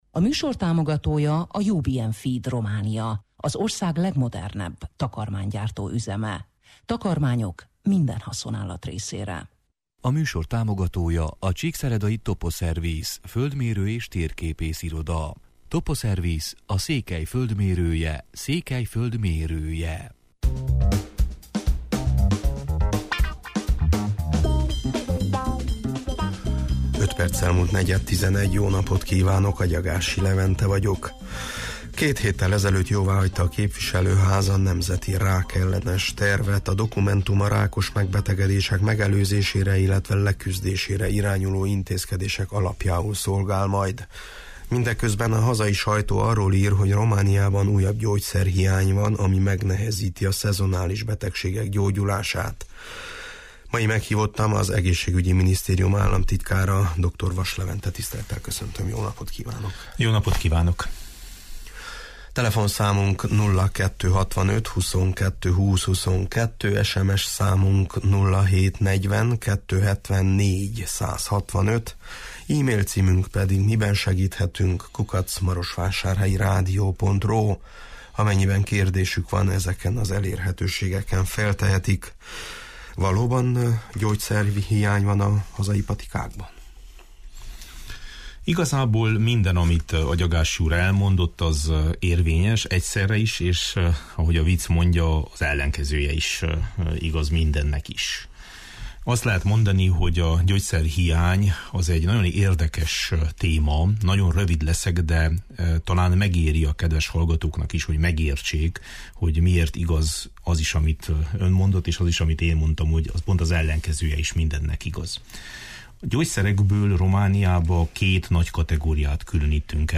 Meghívottam az Egészségügyi Minisztérium államtitkára, dr. Vass Levente: